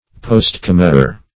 Search Result for " postcommissure" : The Collaborative International Dictionary of English v.0.48: Postcommissure \Post*com"mis*sure\, n. [Pref. post- + commisure.]
postcommissure.mp3